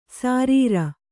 ♪ sārīra